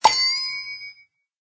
mailbox_alert.ogg